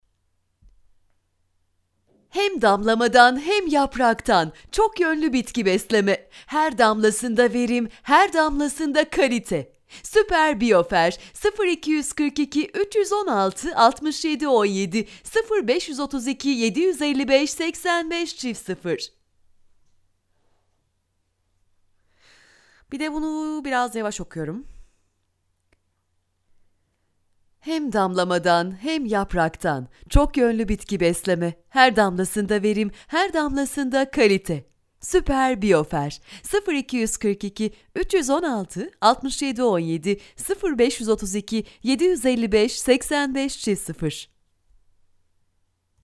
Hello I am professional Turkish voice artist.
I have my own recordig studio.
Kein Dialekt
Sprechprobe: eLearning (Muttersprache):